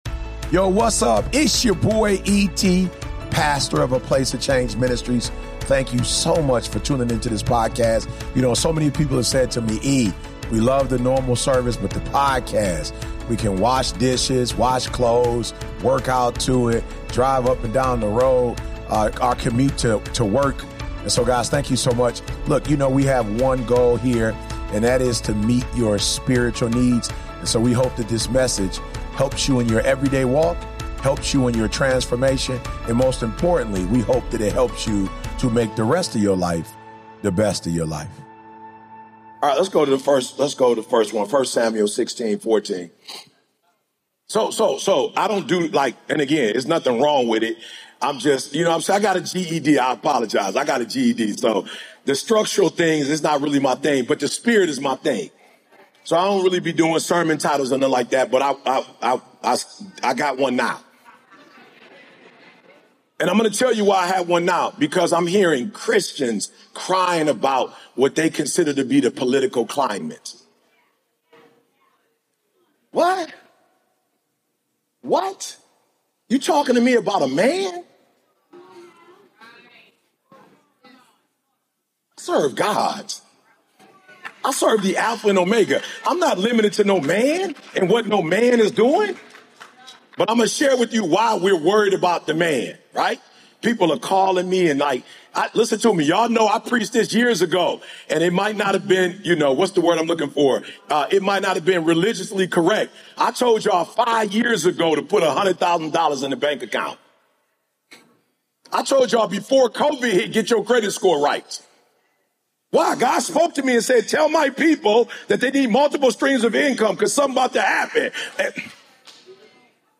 This sermon is a gut check! Stop living in the bare minimum and step into the greatness God has for you.